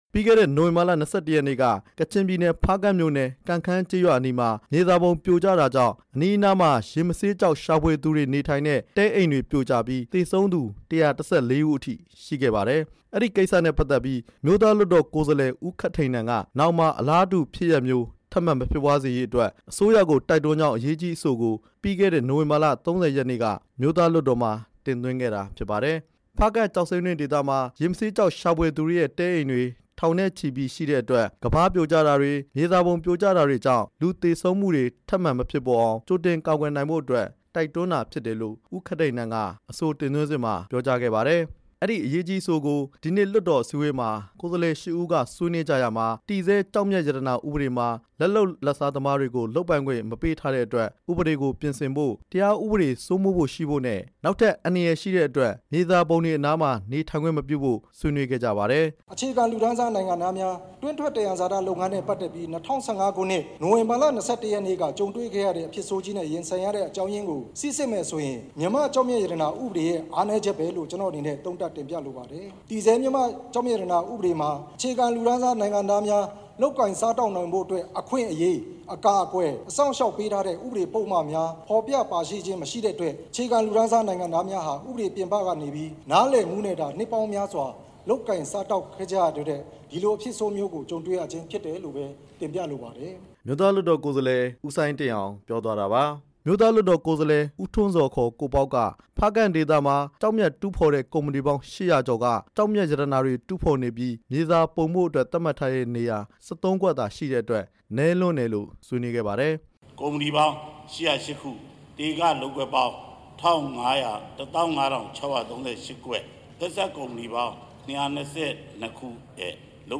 ကိုယ်စားလှယ်တချို့ရဲ့ ဆွေးနွေးချက်